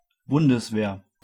Ääntäminen
Synonyymit Militär Ääntäminen Tuntematon aksentti: IPA: /ˈbʊndəsˌveːɐ̯/ Haettu sana löytyi näillä lähdekielillä: saksa Käännöksiä ei löytynyt valitulle kohdekielelle.